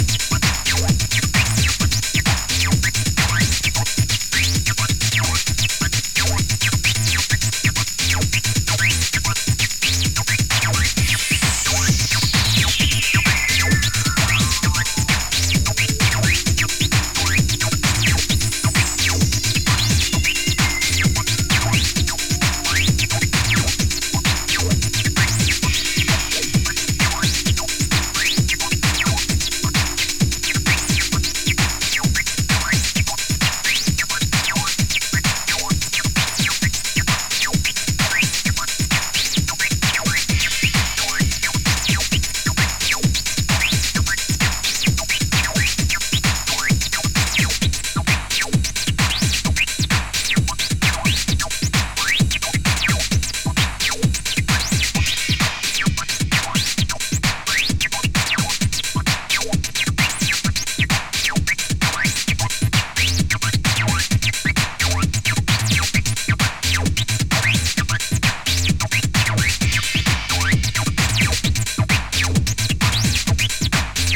妖しいAcid